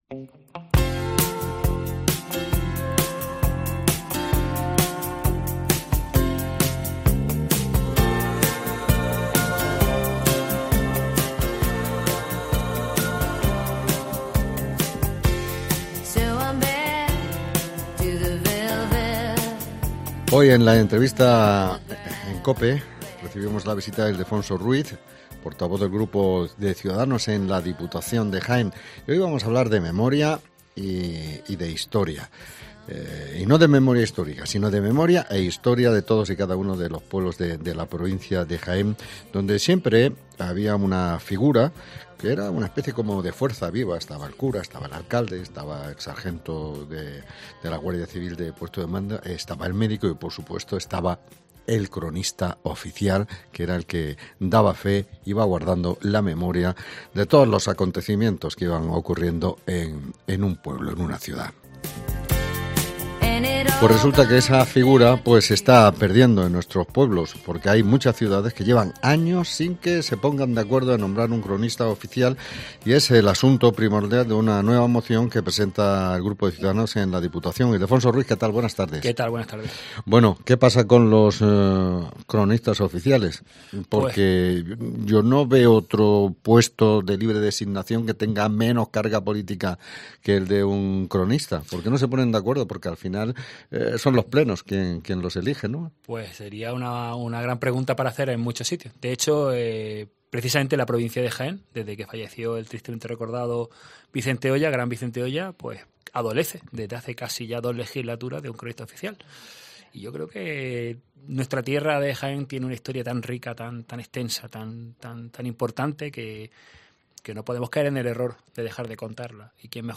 Hoy Ildefonso Ruiz ha visitado los estudios de COPE para darnos todos los detalles